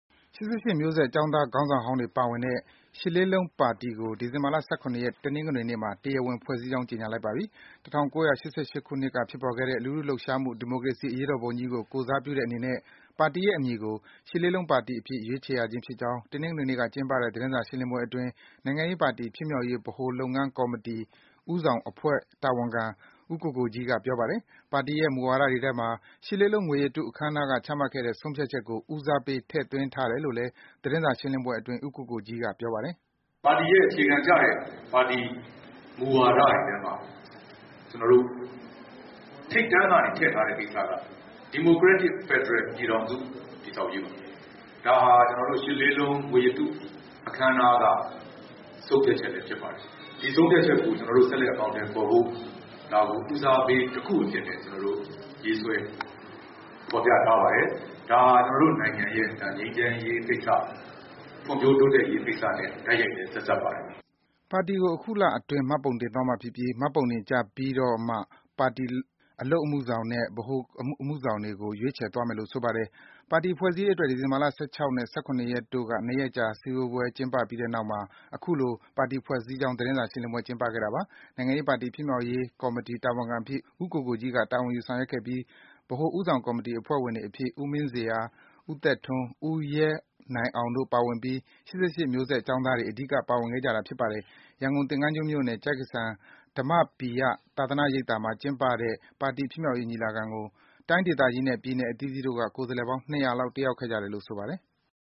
၈၈ မျိုးဆက် နိုင်ငံရေးပါတီ ဖွဲ့စည်းခြင်း သတင်းစာရှင်းလင်းစဉ် (နိုင်ငံရေးပါတီတစ်ရပ် ဖြစ်မြောက်ရေးကော်မတီ)